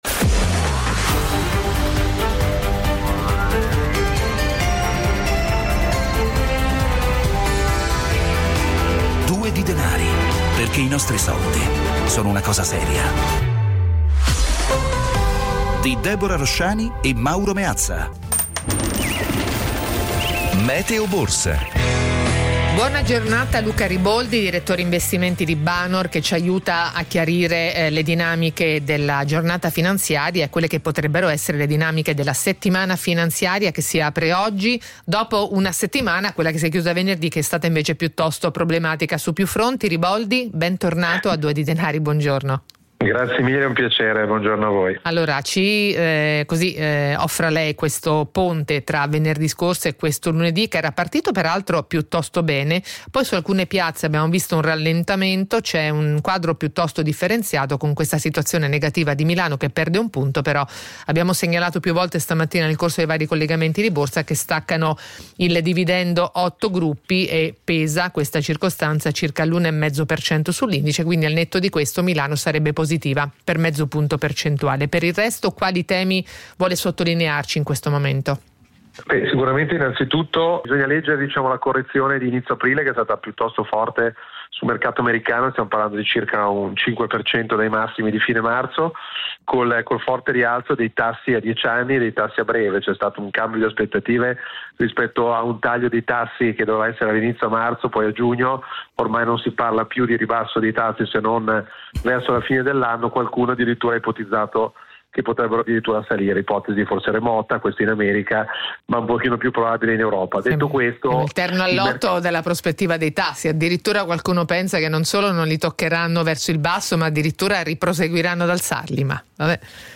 ospite della trasmissione “Due di Denari” in onda su Radio 24 (audio)